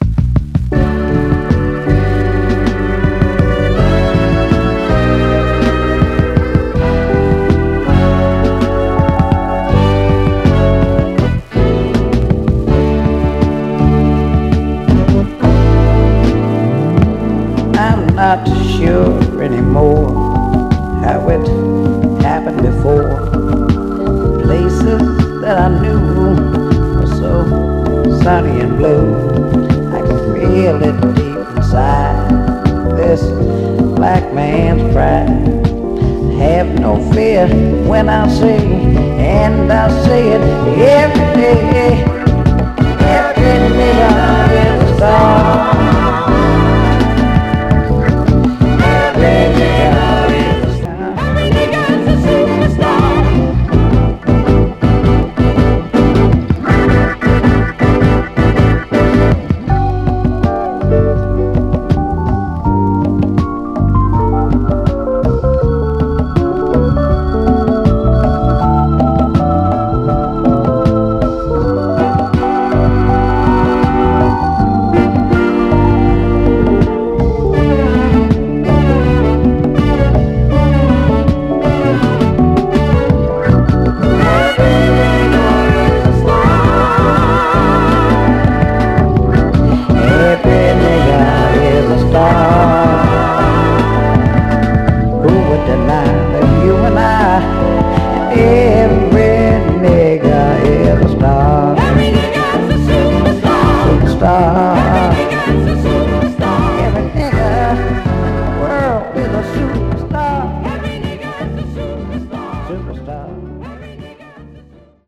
後半のエレピ展開も最高なテイクです！
ただしこのタイトル特有のプレスノイズあり。
※試聴音源は実際にお送りする商品から録音したものです※